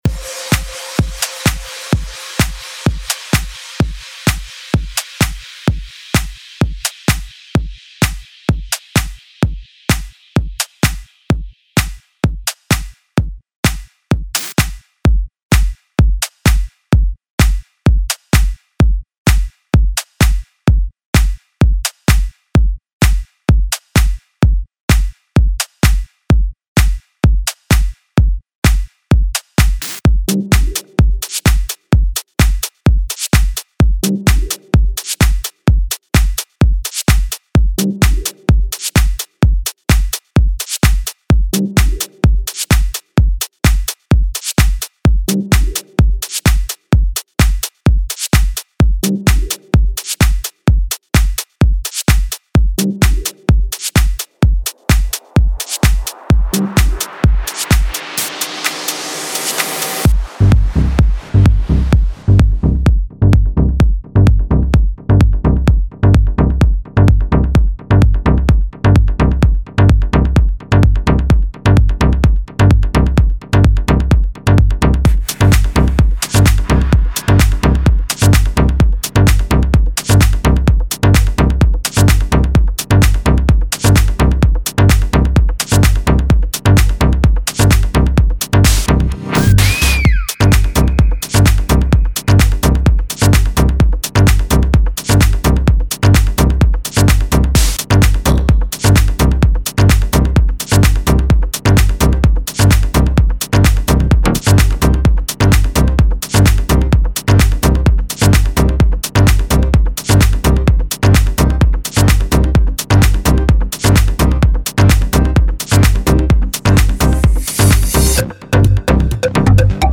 It's a electronic music genre, called tech house.